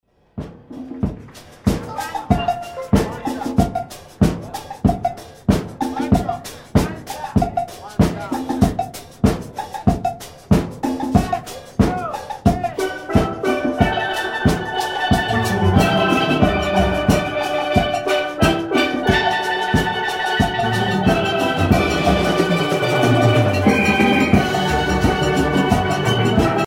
danse : calypso
Pièce musicale éditée